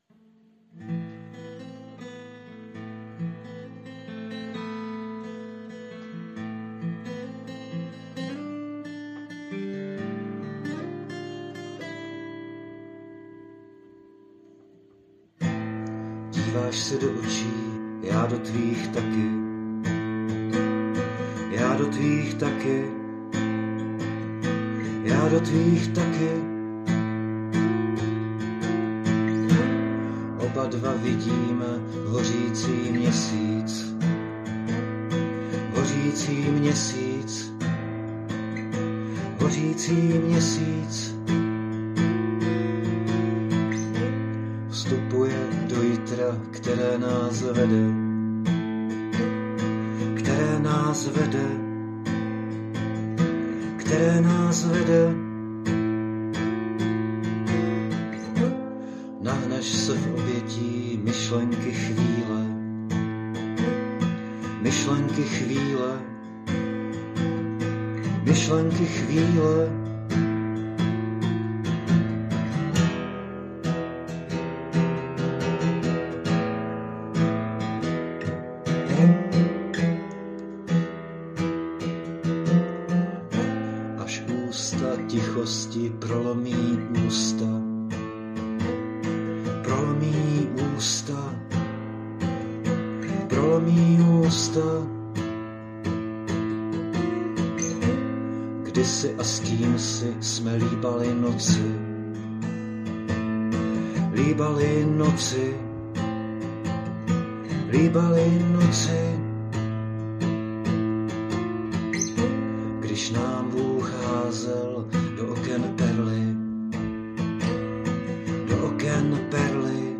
Na začátku bylo jediné pravidlo, myšlenka - zvedni kytaru, neser se s tim, nahraj to na jeden jedinej pokus a vrhni to ven.